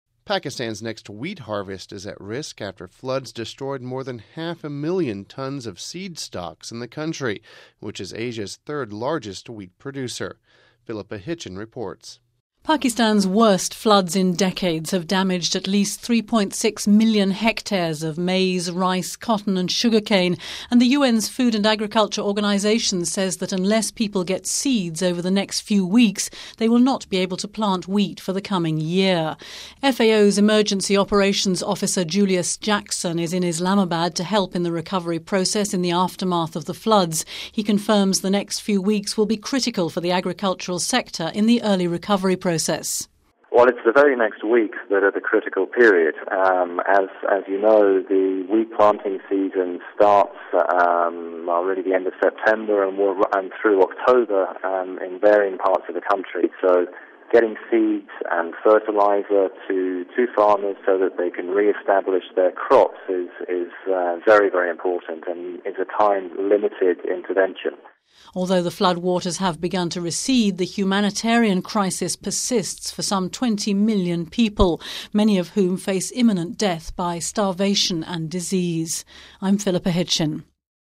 (01 Sept 10 - RV) Pakistan's next wheat harvest is at risk after floods destroyed more than a half-million tonnes of seed stocks in the country, which is Asia's third-largest wheat producer. We have this report...